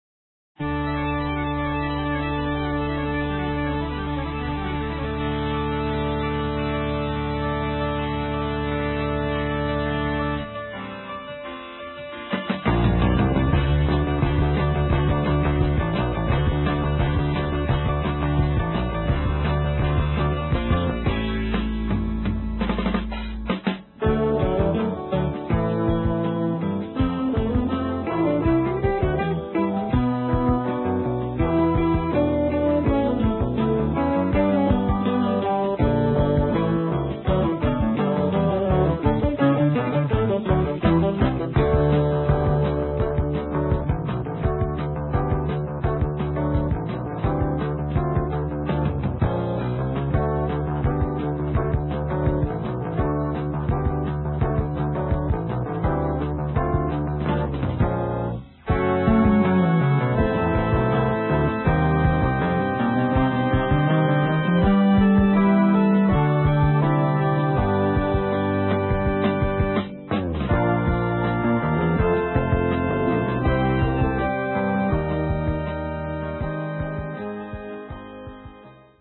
Mono, 1:18, 16 Khz, (file size: 155 Kb).